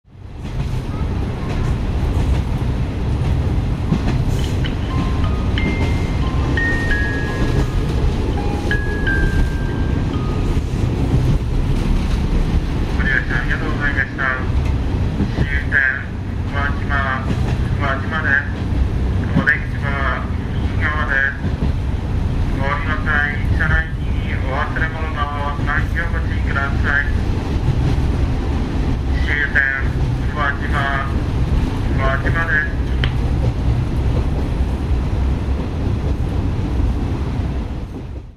�@��������SONY��MD MZ-R50�Ř^���AMac��MP3�`���ɉ��H���Ă���܂��B